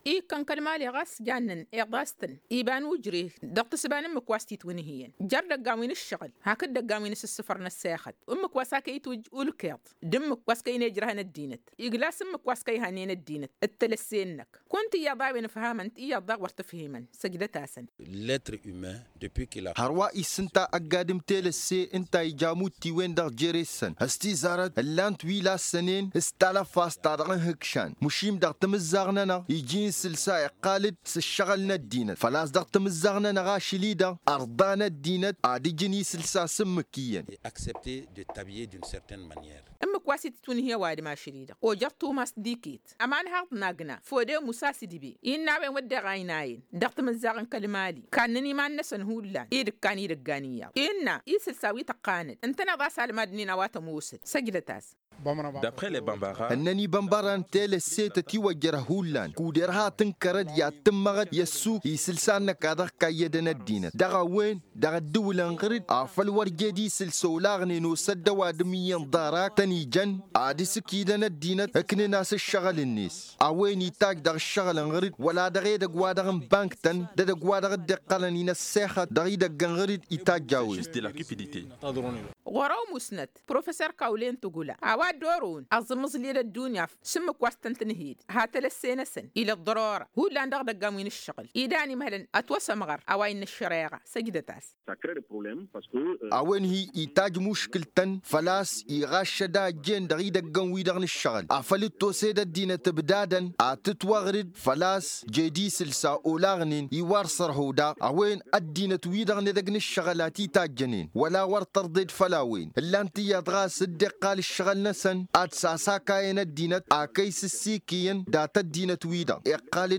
Éléments de réponse dans ce reportage